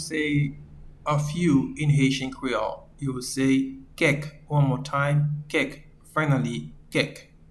Pronunciation:
A-few-in-Haitian-Creole-Kek.mp3